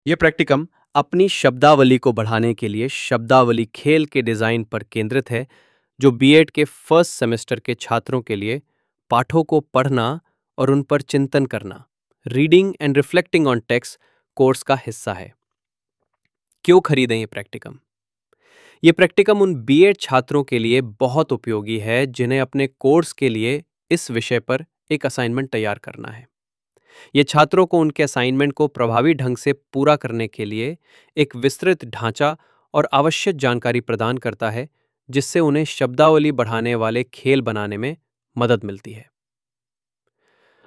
A short audio explanation of this file is provided in the video below.